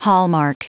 Prononciation du mot hallmark en anglais (fichier audio)
Prononciation du mot : hallmark